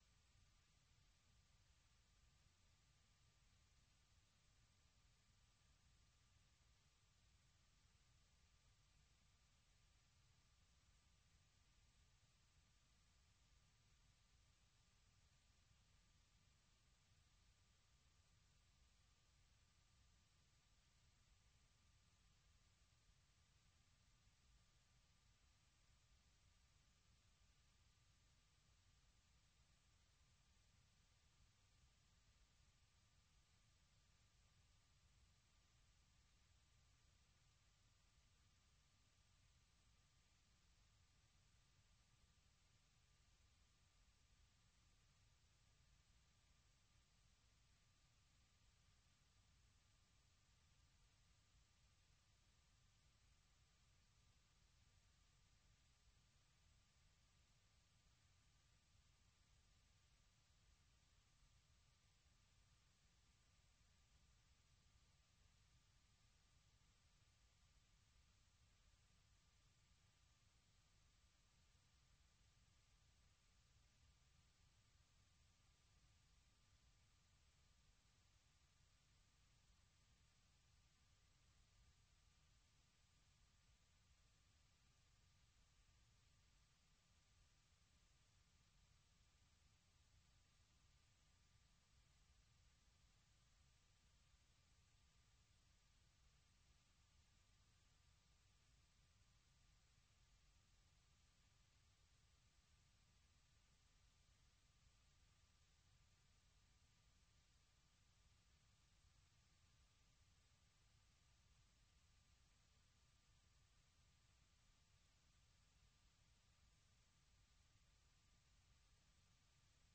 Allocution du président Donald Trump devant la session conjointe du Congrès
Vous suivez sur VOA Afrique l'édition spéciale sur l’allocution du président américain Donald Trump devant une session conjointe du Congrès, suivi de la réponse du Parti démocrate.